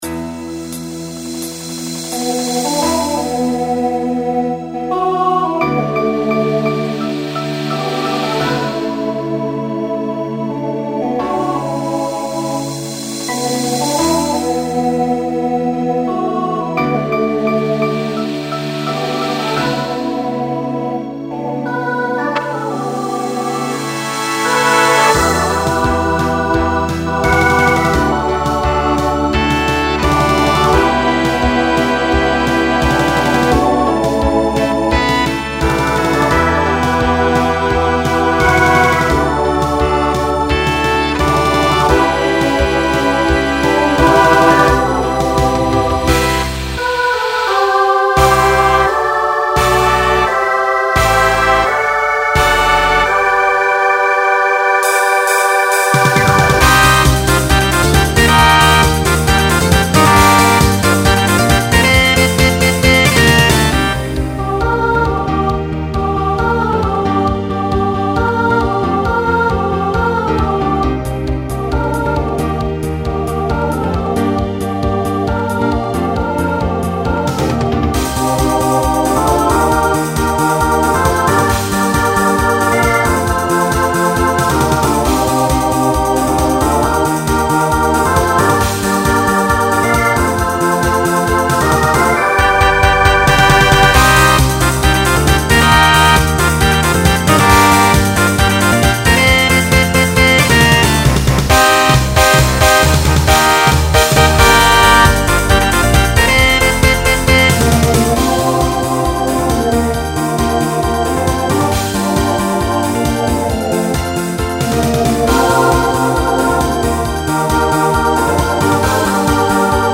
Voicing SSA